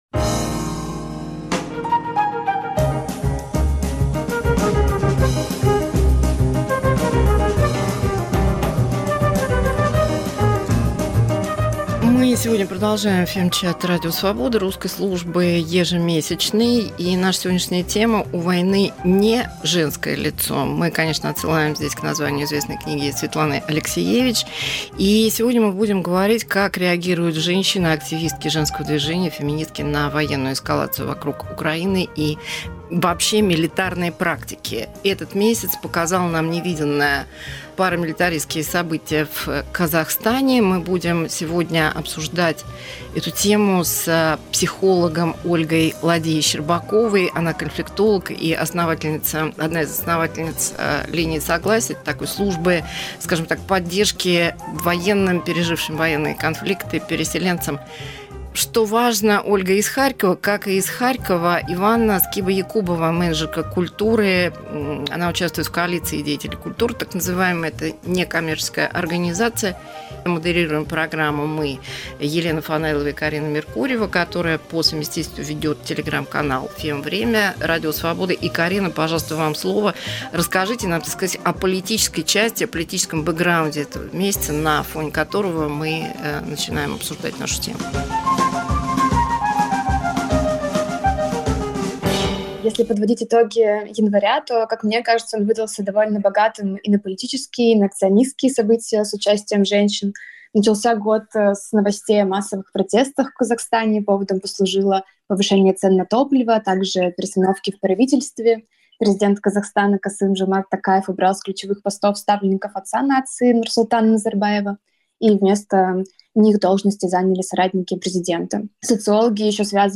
В беседе